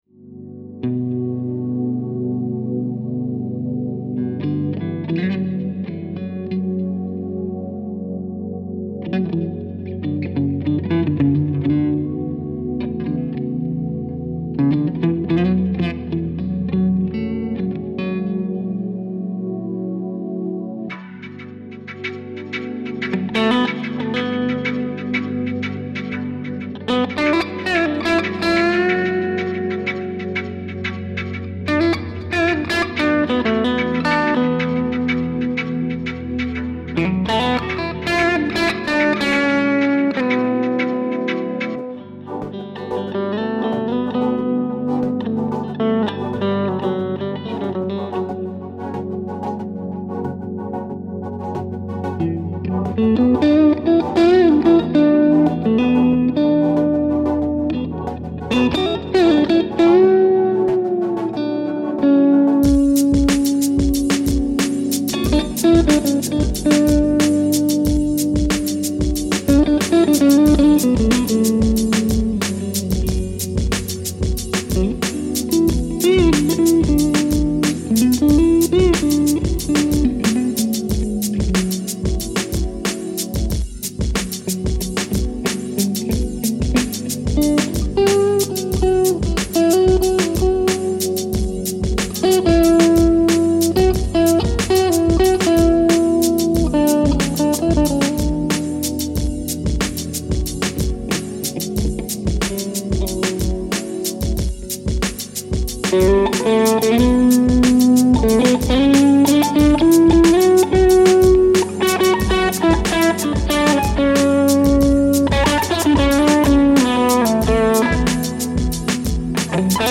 Habe mal schnell was hiphoplastiges mit der Bullet versucht (sorry war nur ein spontaner first Take..). Über den Xotic-Booster direkt in den HX Stomp mit einer AC30 Simulation.
Sind 10er Elixier-Optiweb drauf und die Pickups verschwinden fast in den Korpus. Habe alle fünf Positionen mal angespielt und einmal nach dem Booster noch eine Overdrive und TS dazu gegeben. Anhänge hiphoplay.mp3 4,8 MB · Aufrufe: 205